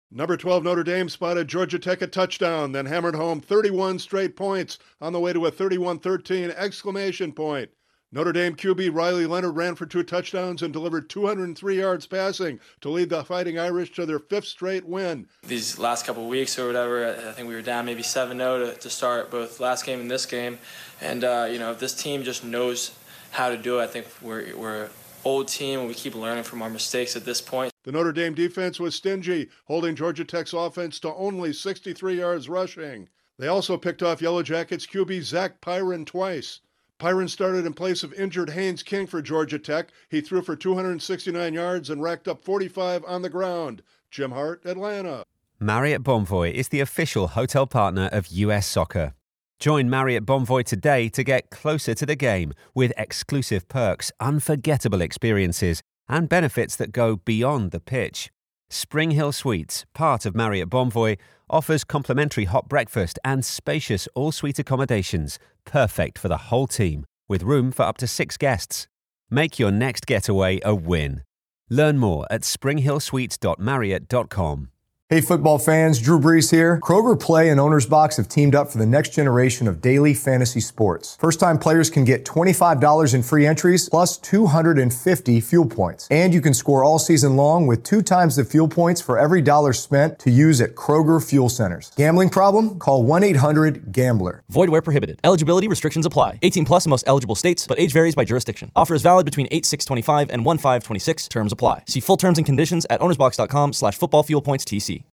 Notre Dame shakes off a slow start to beat Georgia Tech in Atlanta. Correspondent